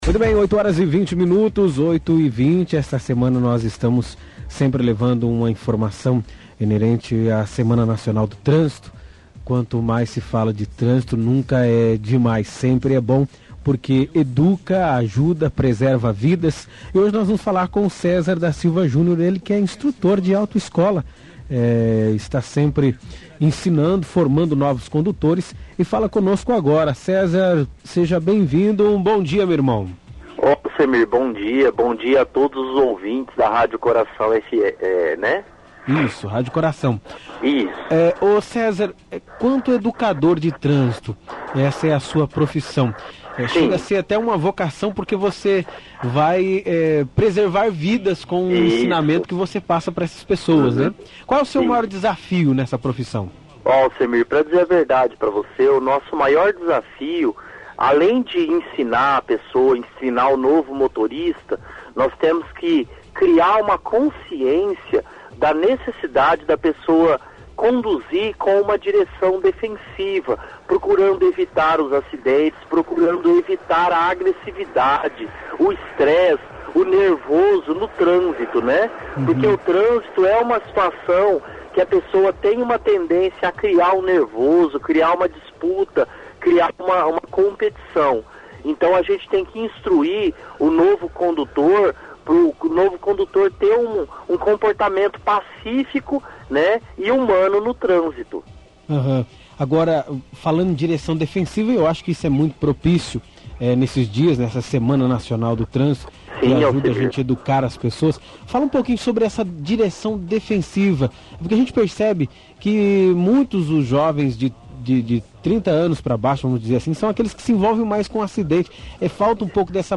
Entrevista Instrutor de trânsito.mp3 6M